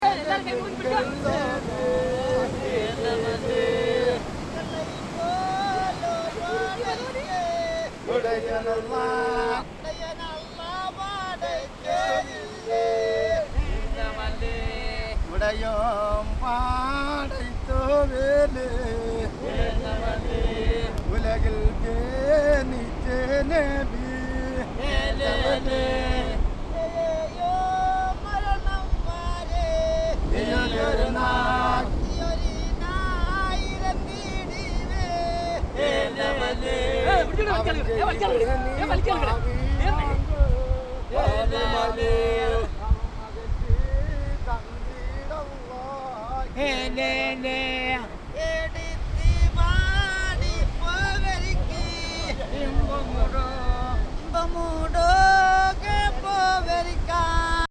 Early on the morning, Kovalam's fishermen bring back the net on the beach. They pull together on a long rope, this is exhausting. They take it in turns, and song to bring them courage.